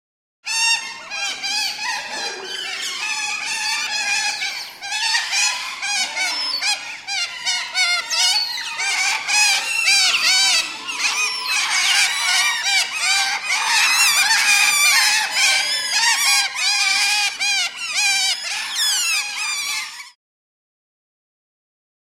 Маленькие выдры громко кричат вместе в зоопарке